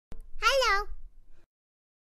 hello-baby-girl-sound-effect-192-kbps.mp3